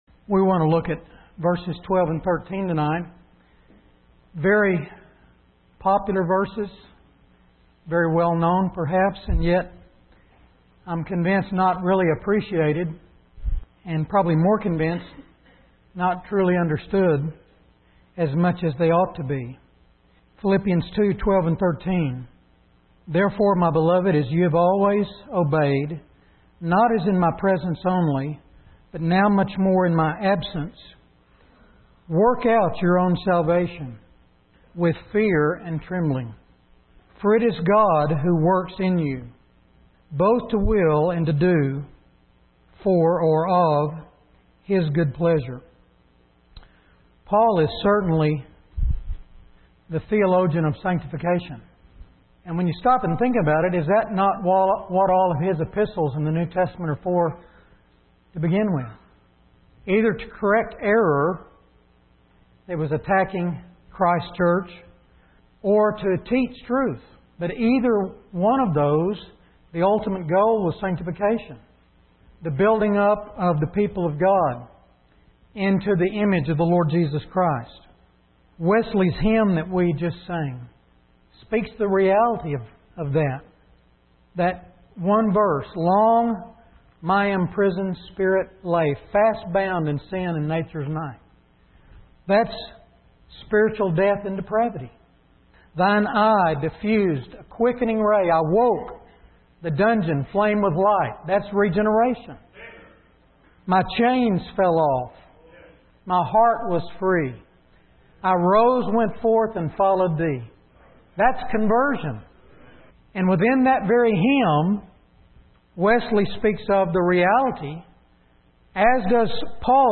In this sermon, the preacher emphasizes the work of God in our salvation and sanctification. He highlights the immense sacrifice of God giving His Son for sinners and how this should assure us that He will also change, sanctify, and deliver us from besetting sins. The ultimate goal of our salvation is sanctification, which involves being built up into the image of Jesus Christ.